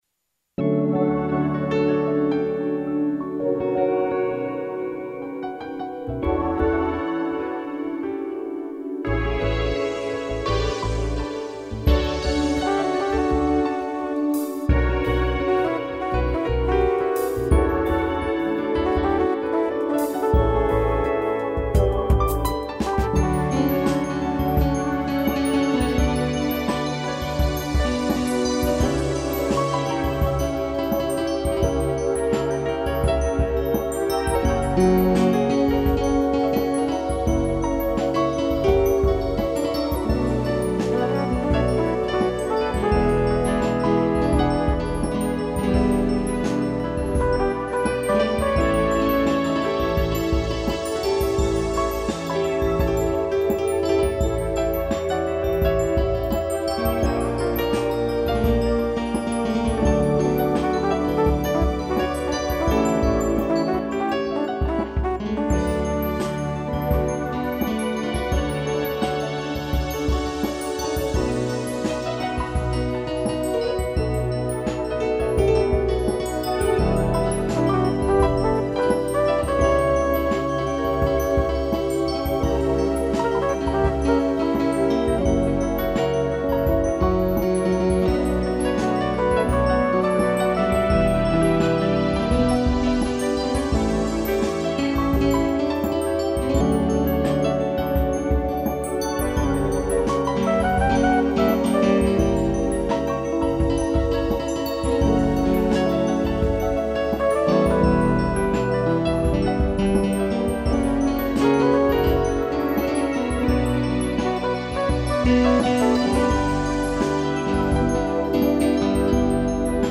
piano e Fugel Horn
instrumental